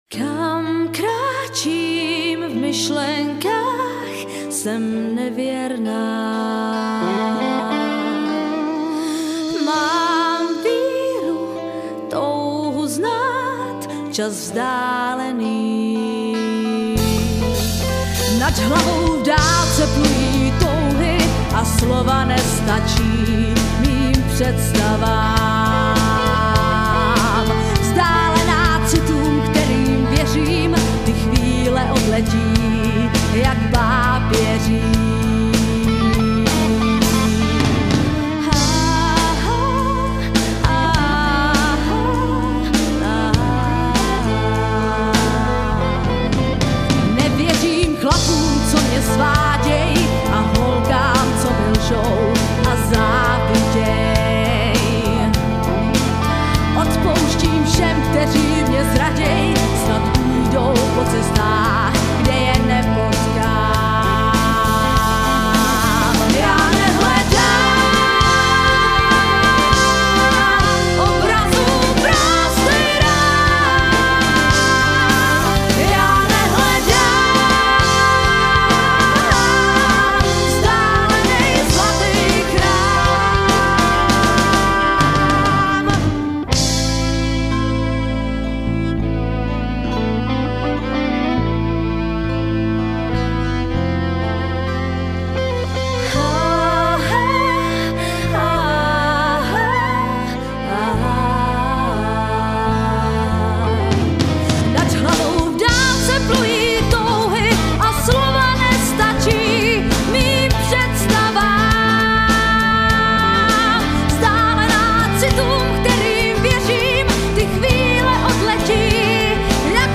kytary
basovß kytara